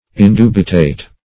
Indubitate \In*du"bi*tate\, a. [L. indubitatus; pref. in- not +